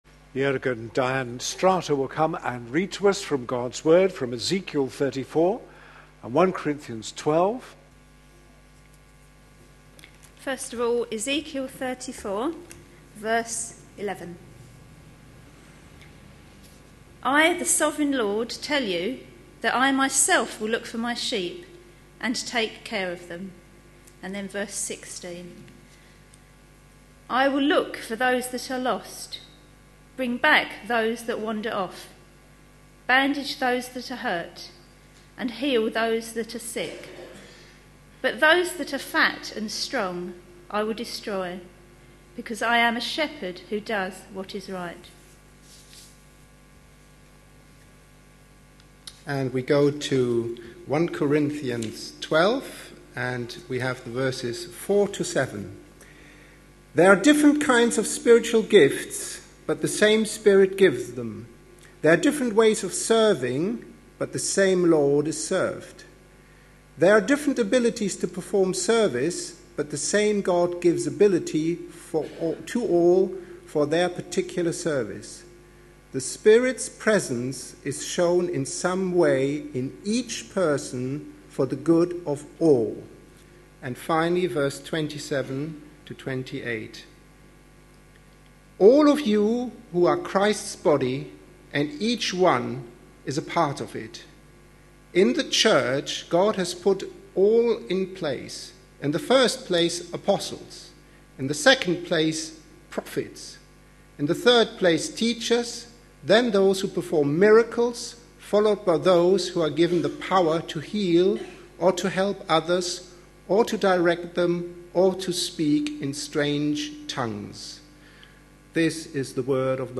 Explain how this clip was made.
(Slightly poor sound quality near the end.)